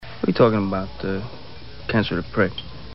his voice.